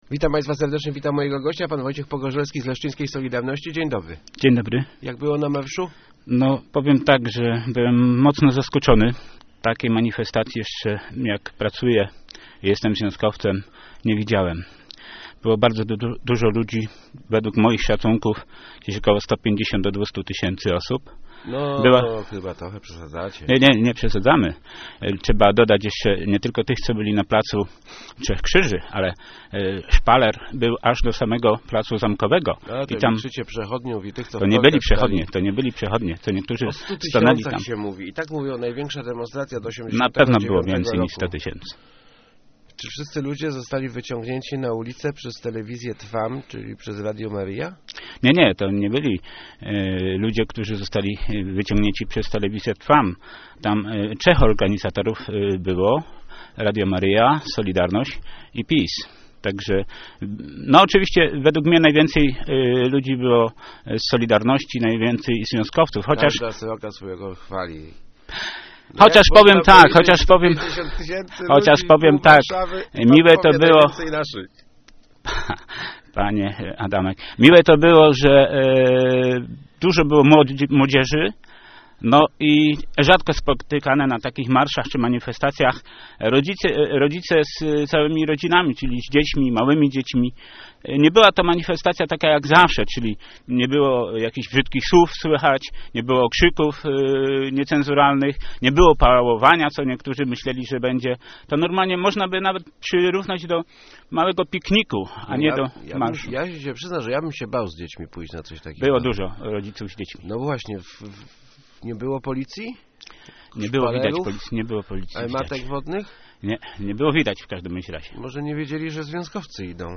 Start arrow Rozmowy Elki arrow Co dał ten marsz?